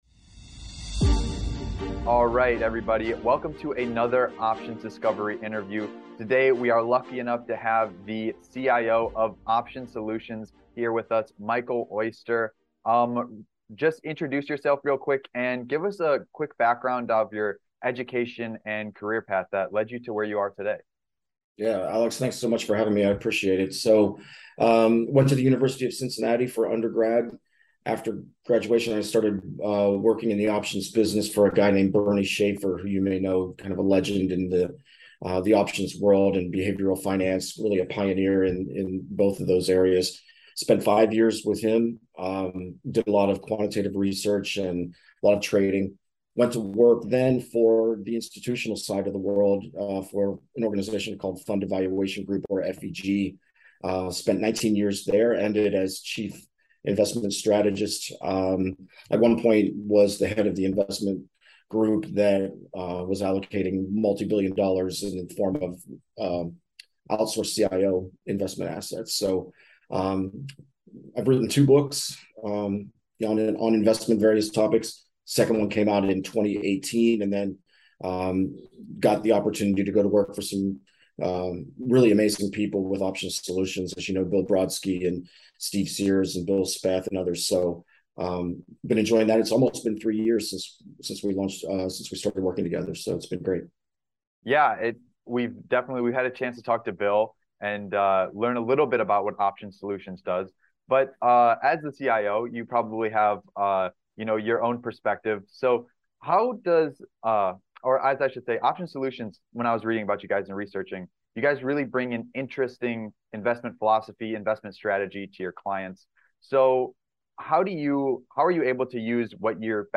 COMPLETE INTERVIEW: USING OPTIONS FOR RISK MANAGEMENT AND PROTECTIVE PUTS